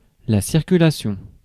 Ääntäminen
US : IPA : /ˈsɝkjʊˌleɪʃən/